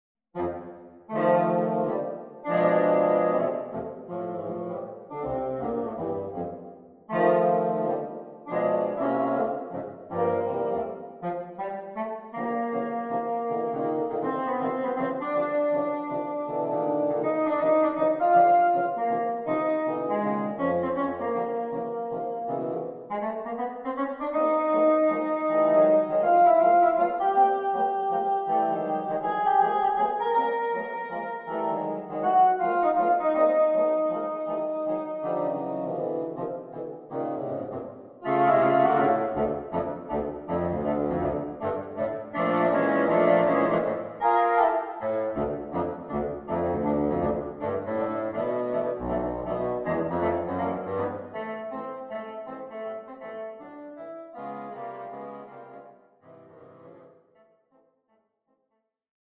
The style of the piece is rooted in ‘big band’ sounds.
4 Bassoons (No contra required).